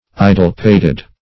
Idle-pated \I"dle-pat`ed\, a. Idle-headed; stupid.